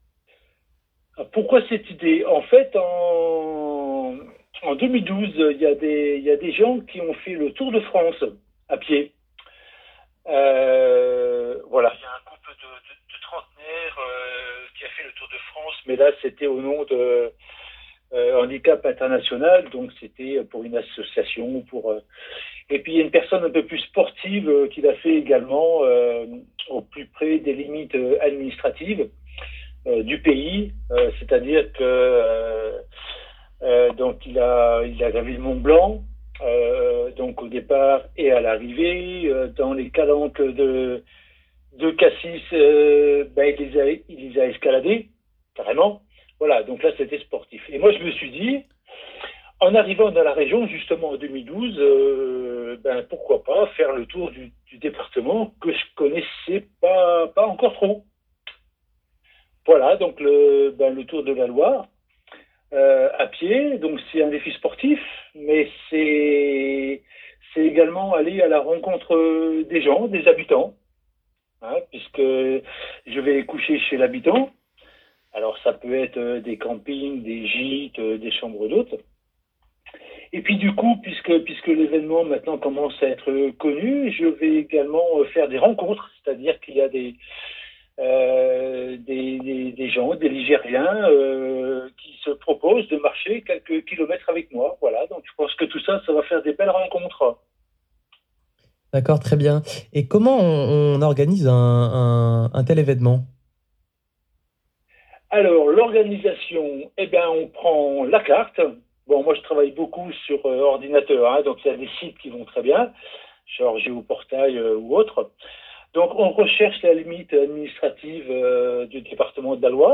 Les news du moment (vidéo)                                                   Les informations (vidéo)       L'interview complète (audio)